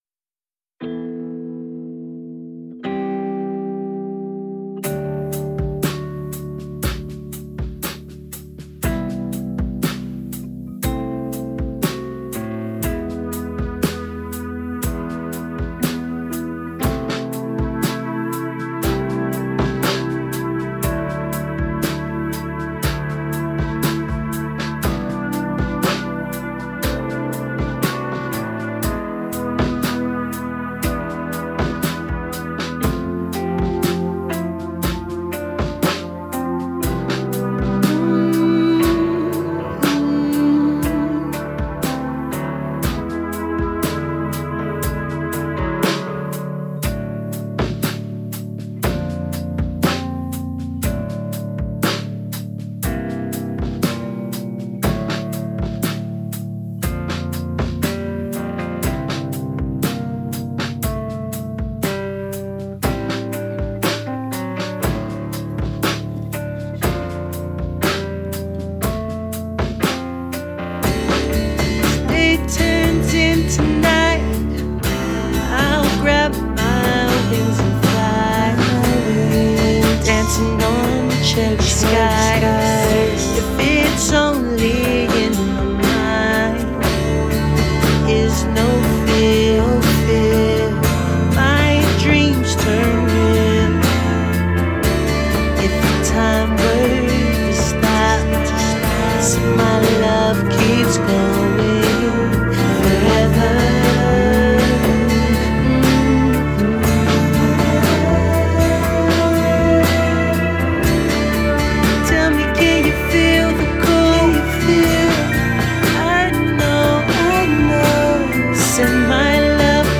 A 'downtempo' song. I am not the singer, but it is my composition (I played all instruments except drums, recorded and mixed the music) Let me know what you think. thanks and Happy holidays